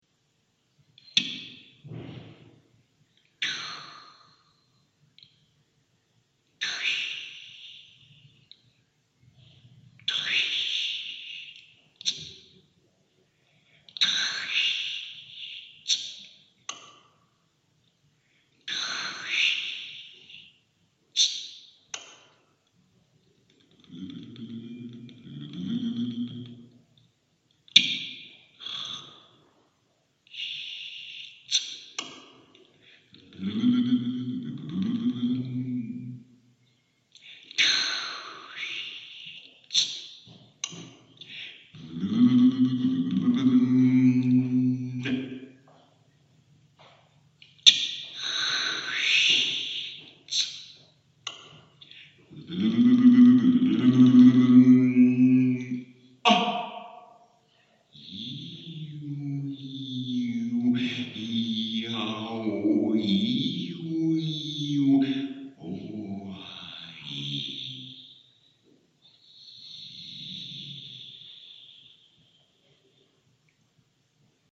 Aufnahmen von der Lesung in Leipzig mit Lautgedichten
Lautgedicht 1